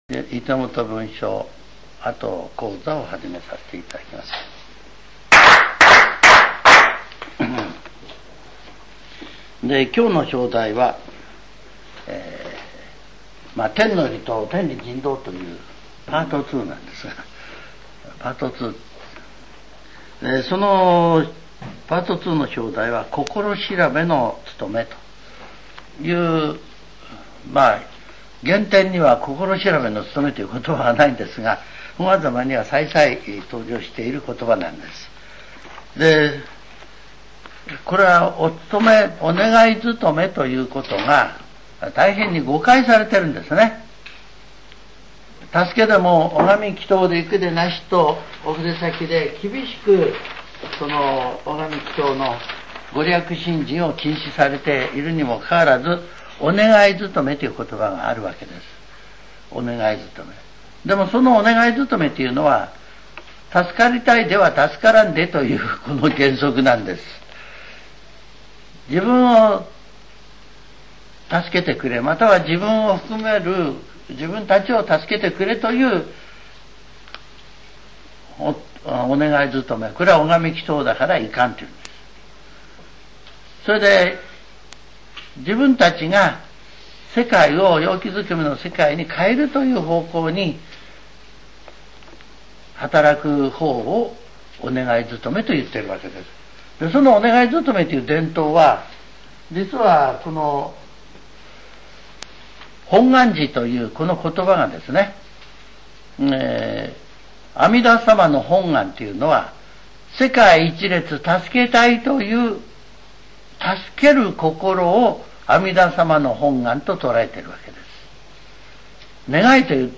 櫟本分署跡講座 ２００５年０５月２５日CD顕正教祖伝第３7回 「天の理と天理人道」第２部 （心調べのおつとめ）